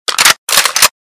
be_loaded.mp3